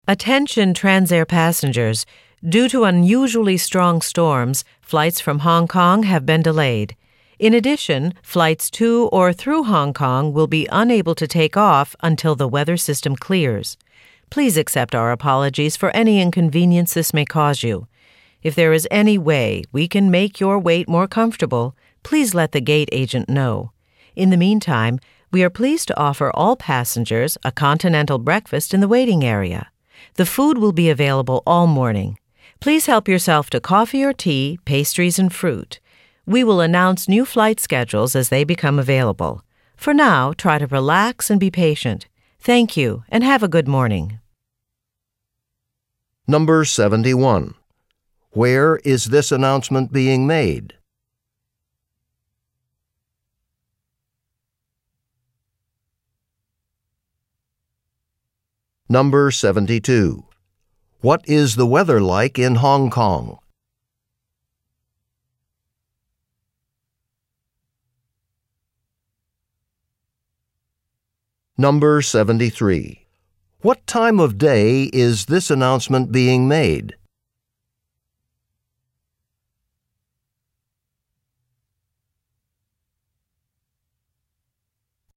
You will hear some talks given by a single speaker.
Wher is the woman talking ?
3: What time of day is this announcement being made?